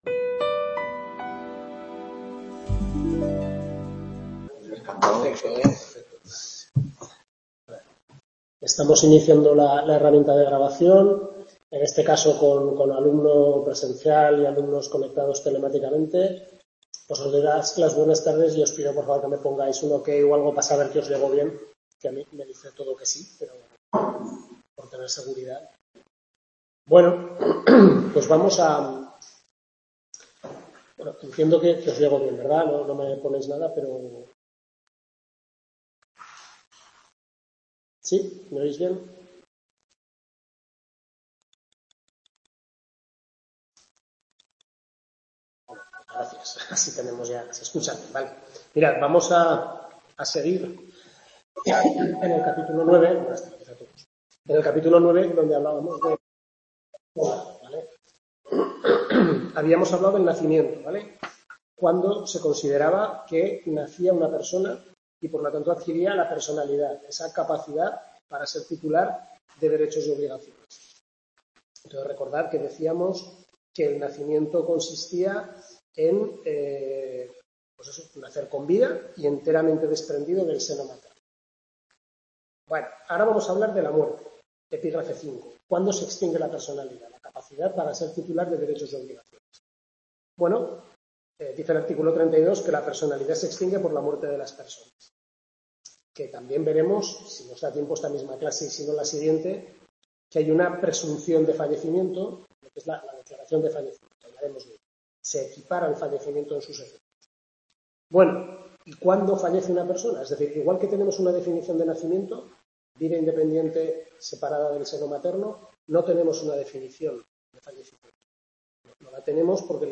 Tutoría 3/6 Civil I (Personas) centro UNED Calatayud